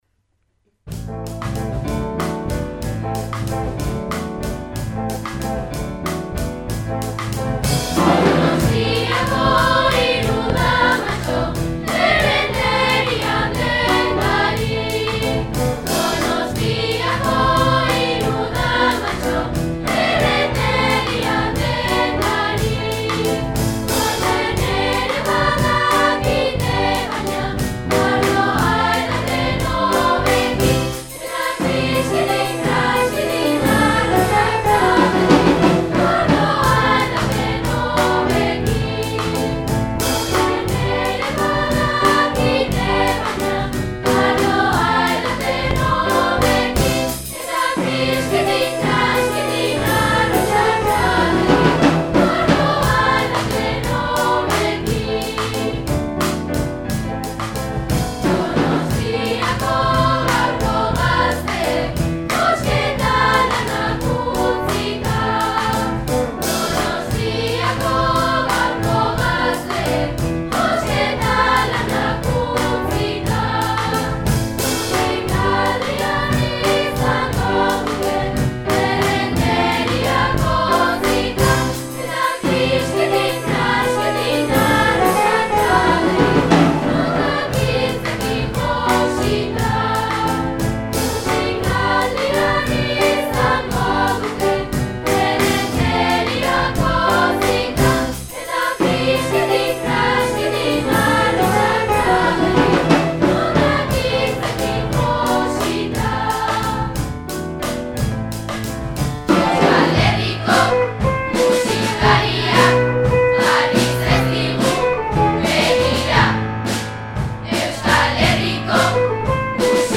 Orereta Abesbatzakoak sintonia abesten
Donostiako hiru damatxo abesti klasikoaren moldaketa da sintonia.
Musikaldiko protagonistak gazteak direnez, gaur egungo doinuekin moldatu dugu” Izan ere, azkarragoa izateaz, rap ukitu bat ere eman diote abestiari.